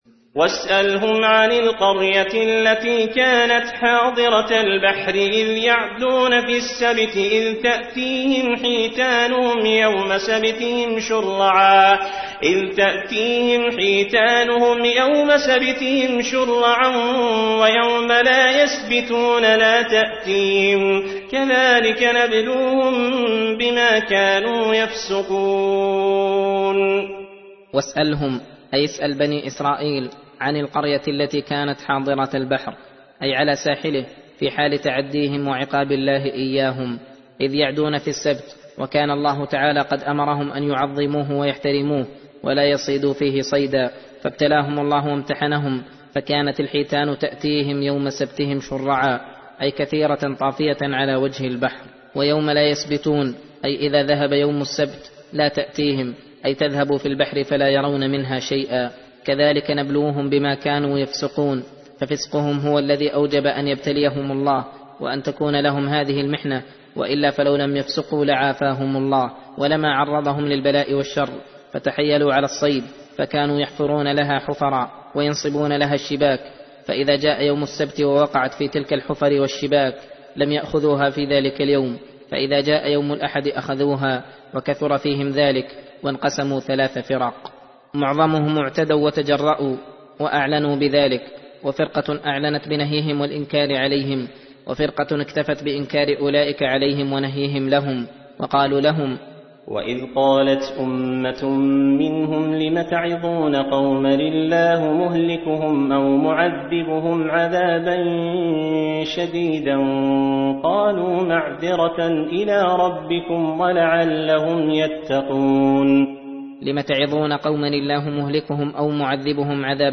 درس (9) : تفسير سورة الأعراف (163-179)